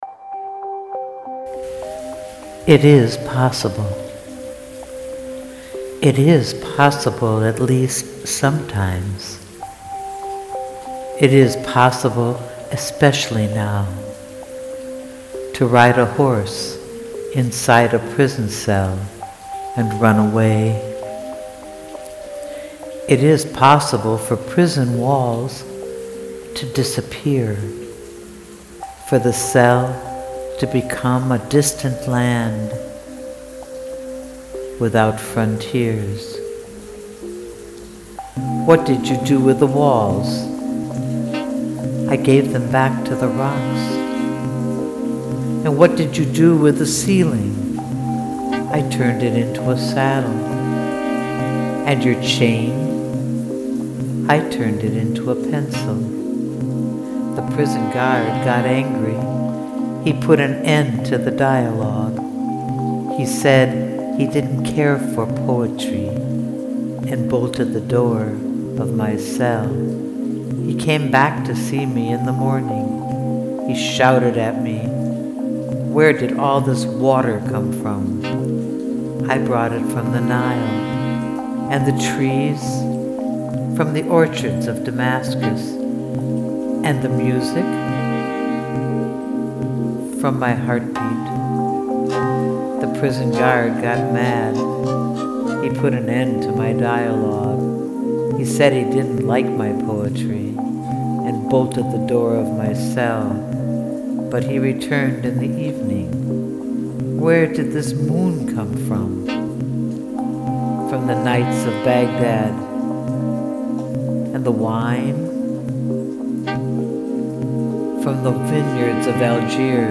Please Press Play to hear my interpretation(with my own music) of this amazing poem!!